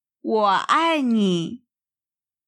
私はあなたを愛しています。 Wǒ ài nǐ
ウォ アイ ニー